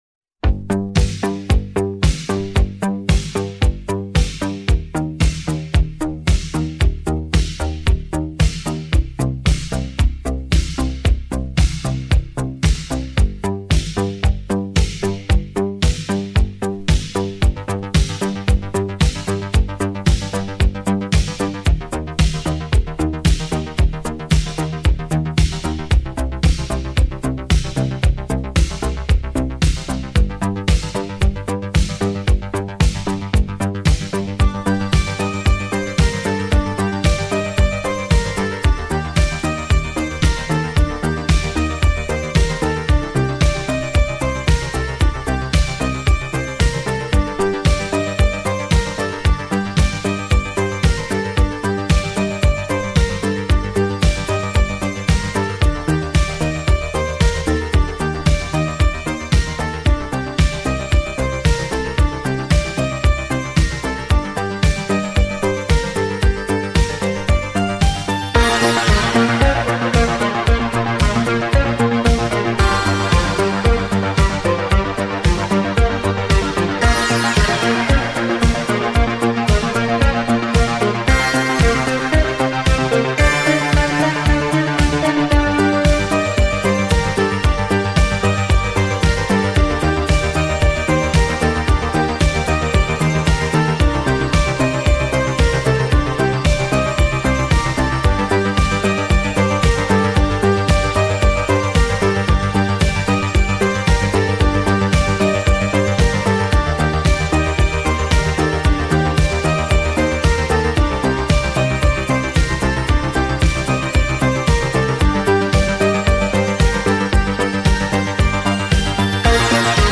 آهنگ بی کلام
سبک این آهنگ Disco بوده